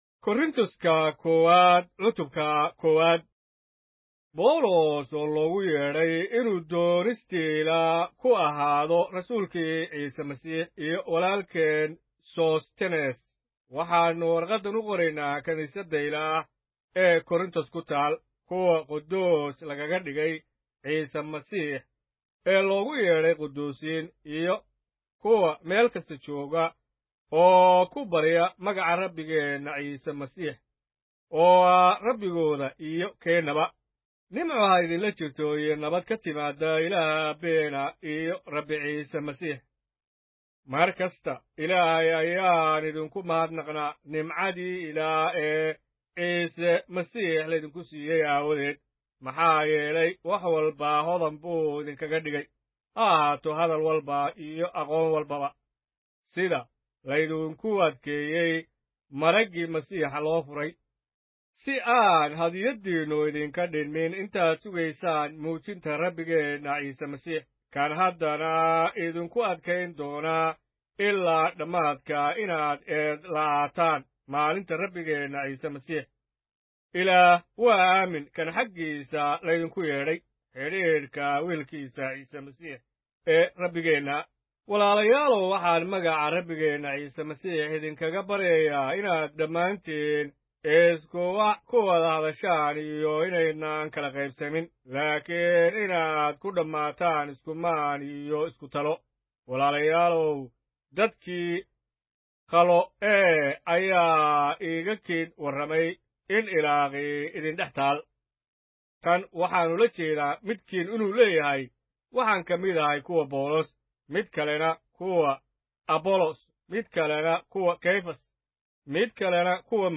Kitaabka Quduuska Ah: 1 Corinthians, chapter 1 of the Somali Bible - with audio narrationIlaah waxa uu doortay waxyaalaha nacasnimada ah ee dunida inuu ceebeeyo kuwa xigmadda. 1Wakorintho-1